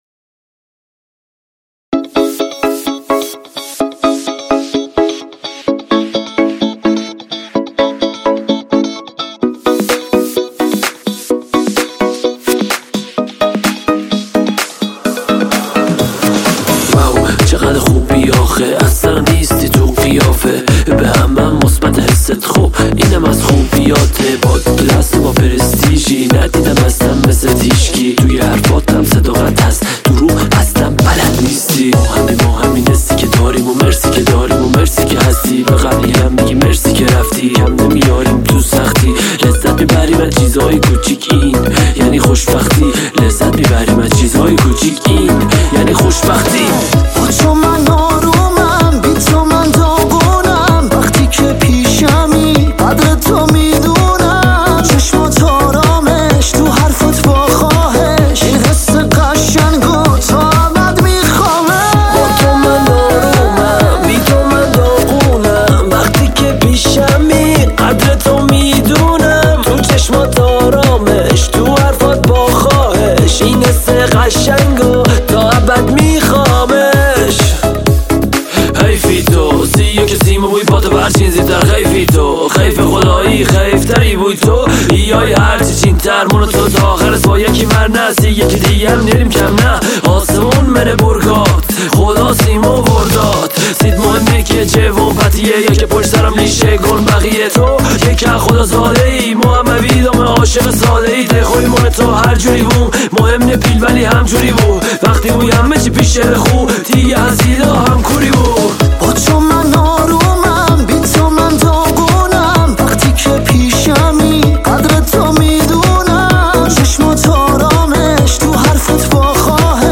موضوعات: تک آهنگ
یه جاهایی که گویش محلی داشت اصلا نفهمیدم !